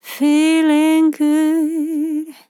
FEELING GOOD CHILL Vocal Sample
Categories: Vocals Tags: CHILL, dry, english, Feeling, female, good, LOFI VIBES, LYRICS, sample
POLI-LYR-FILLS-120BPM-Am-2.wav